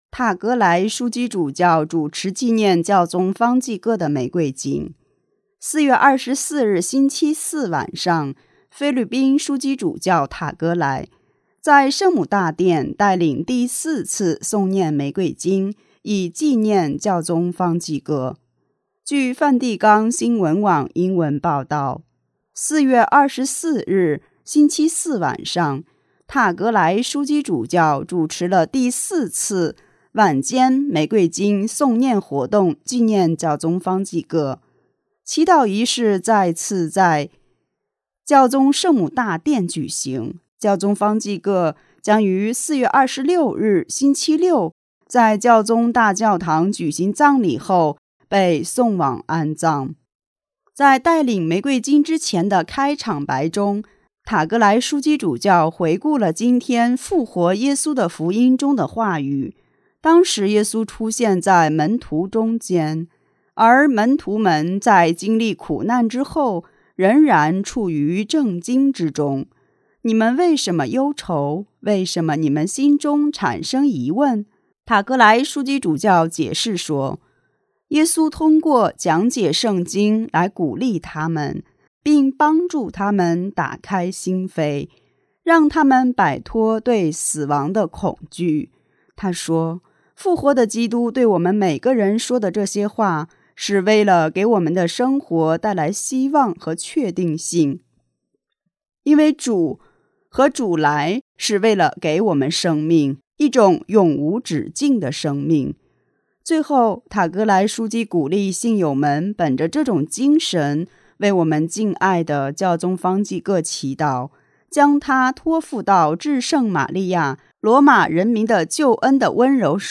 4 24 日星期四晚上，菲律宾枢机主教塔格莱 (Louis Antonio Gokim Tagle) 在圣母大殿带领第四次诵念玫瑰经，以纪念教宗方济各。